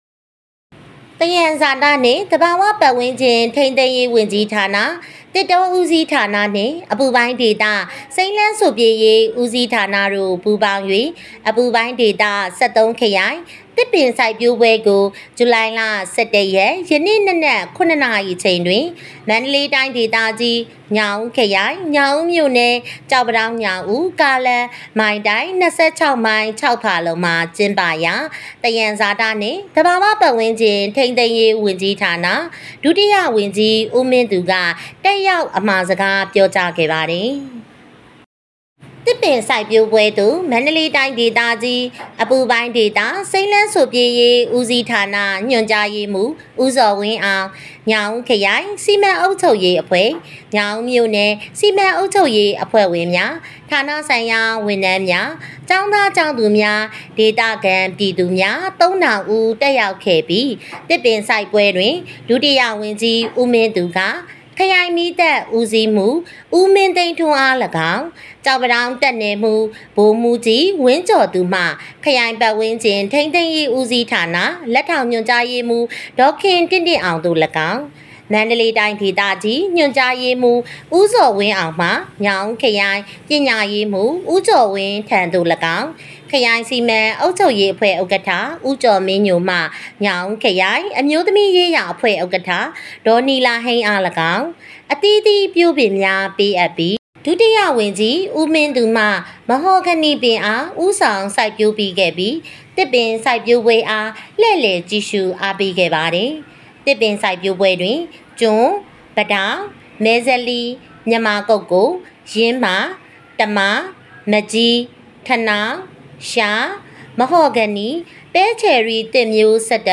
ညောင်ဦးမြို့ အခြေခံပညာအထက်တန်းကျောင်း (မြို့မ) ၌ သဘာဝဘေးအန္တရာယ်ဆိုင်ရာ အသိပညာပေးဟောပြော